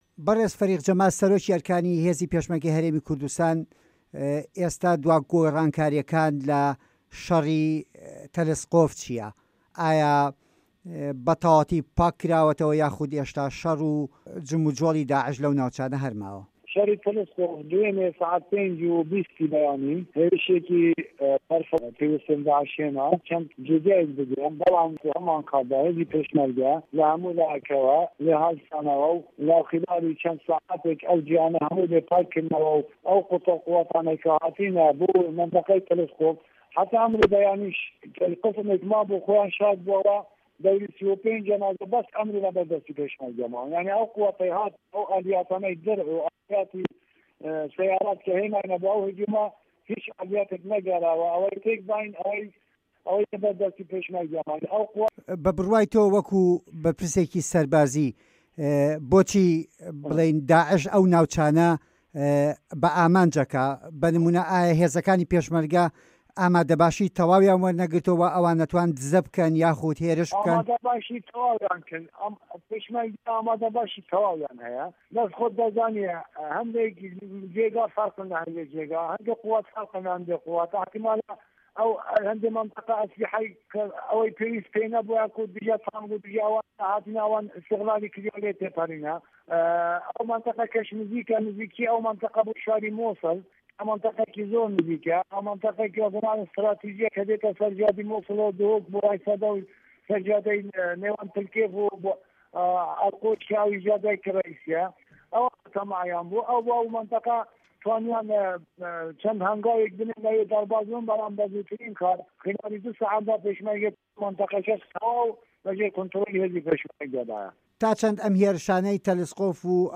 وتووێژ لەگەڵ فه‌ریق جه‌مال محەمەد عومەر